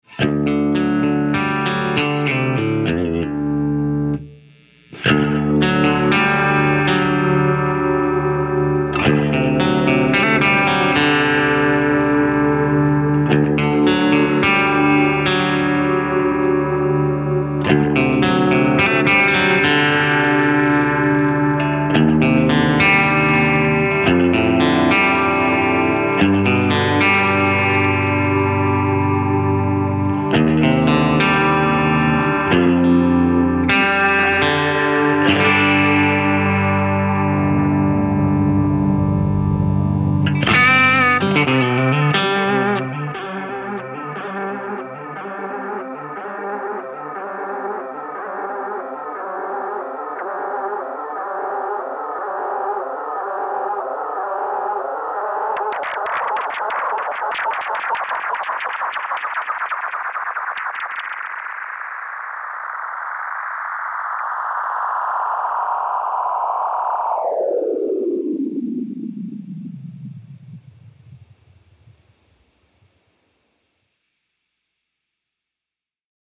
The max Mechanics setting introduces all sorts of mechanical artifacts into the tone here.